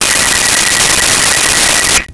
Telephone Ring 02
Telephone Ring 02.wav